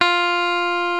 FENDER STRAT 6.wav